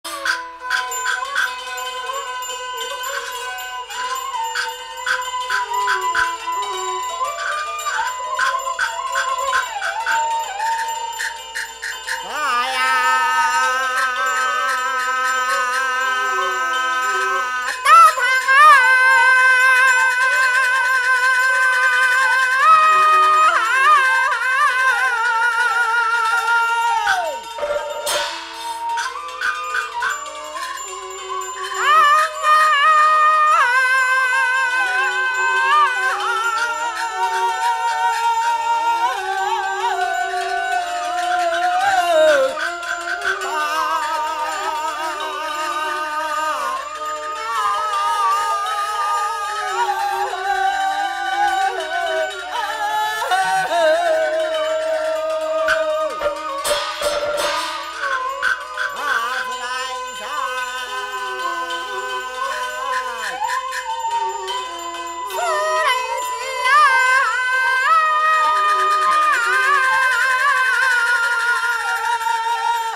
戲曲 - 林沖夜奔選段（倒板） | 新北市客家文化典藏資料庫